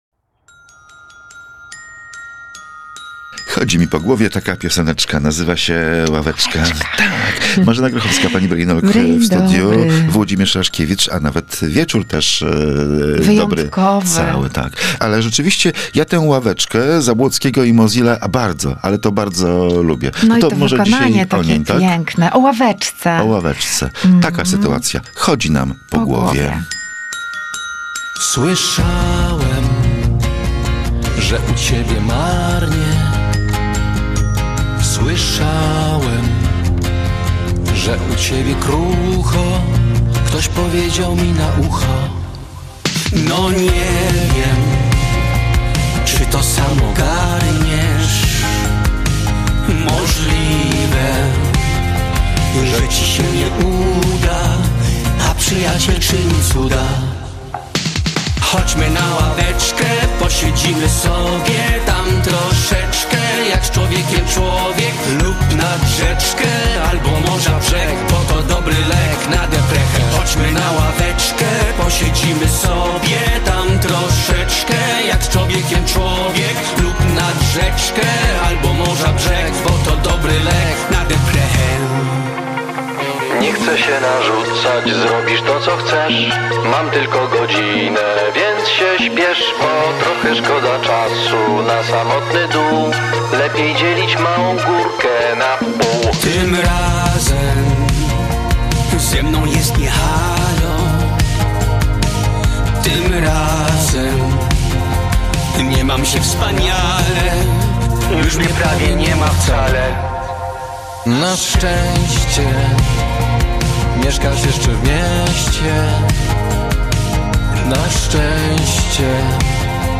Sytuacja na ławeczce. Audycja o wsparciu, pomaganiu i robieniu dobra